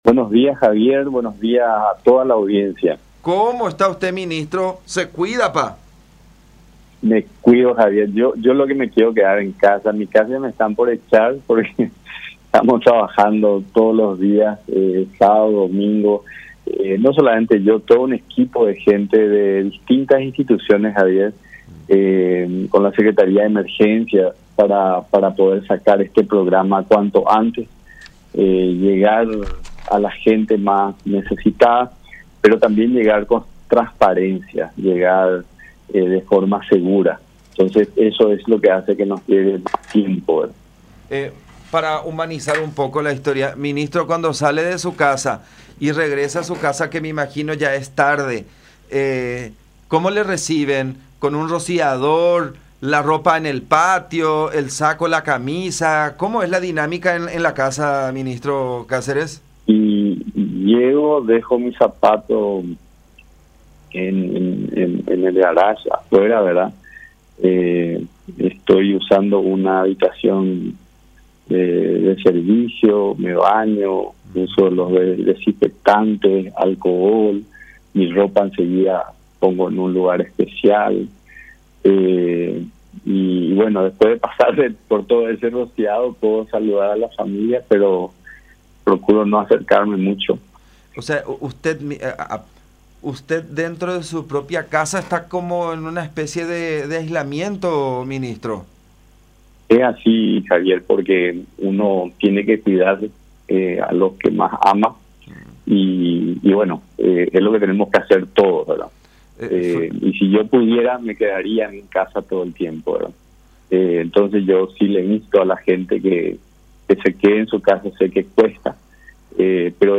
“Hay gente que recibió al instante porque es la que ya tenía el servicios de las billeteras electrónicas”, manifestó Cáceres en contacto con La Unión en referencia al Programa Ñangareko y al trabajo realizado con las empresas telefónicas.